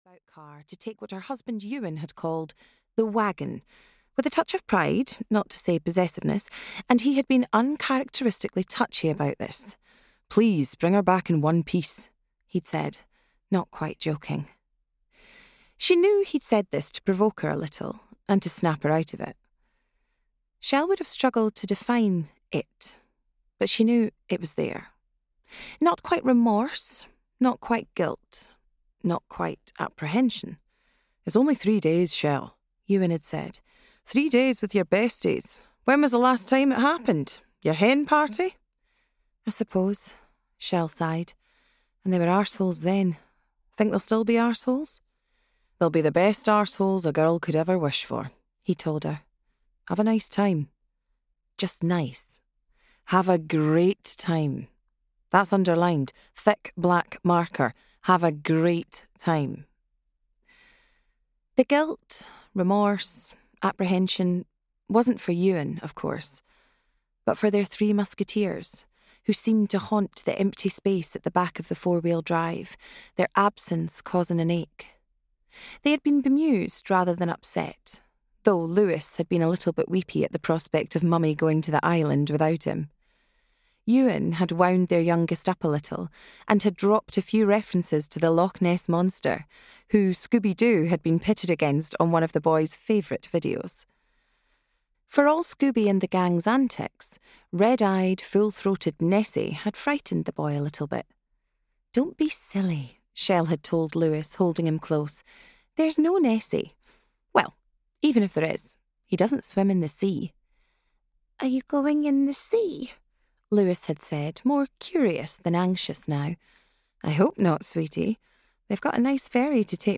The Hunted (EN) audiokniha
Ukázka z knihy